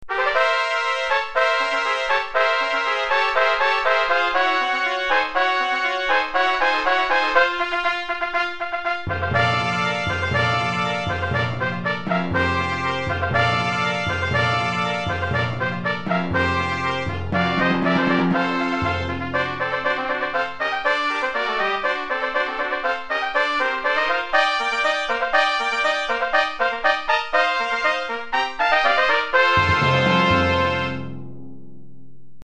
4 Trompettes et Timbales